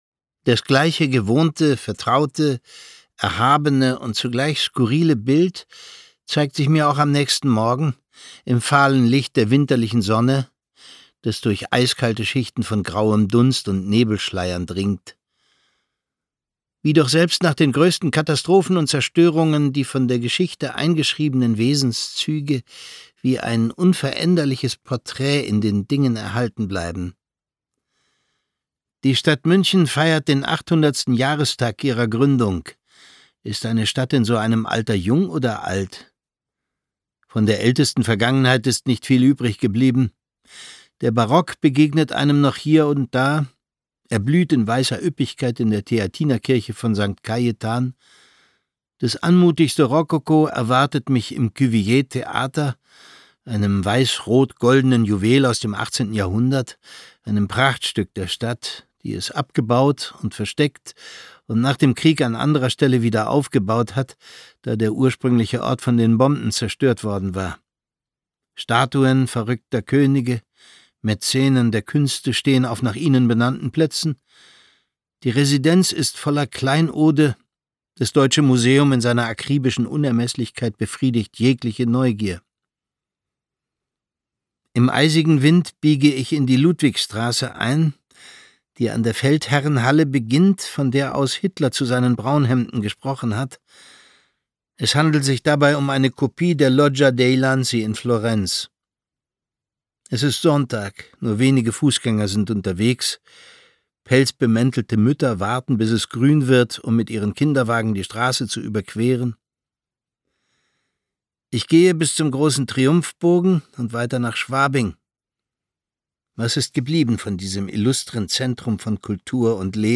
Thomas Sarbacher (Sprecher)
Sein Reisebericht, der sich nie zur Anklage erhebt, besticht durch seinen feinen, warmherzigen Ton, den Thomas Sarbacher eindrucksvoll aufgreift.